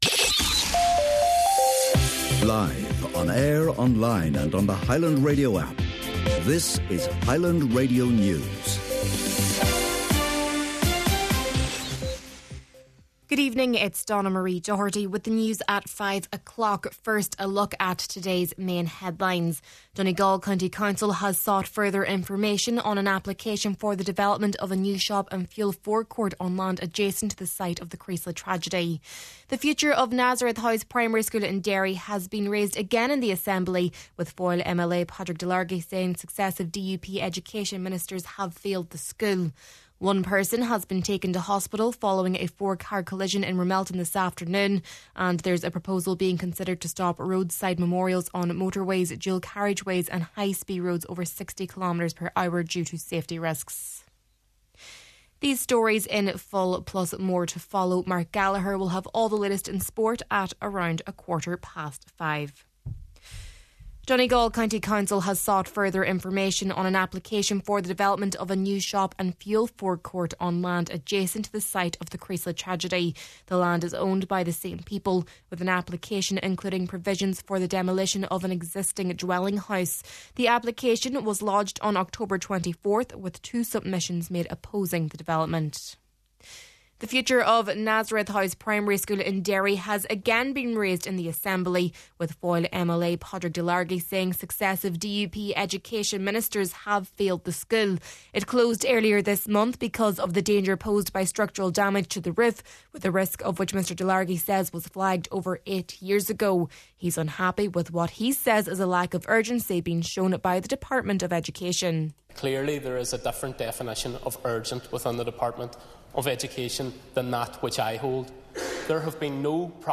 Main Evening News, Sport, An Nuacht and Obituary Notices – Friday, December 12th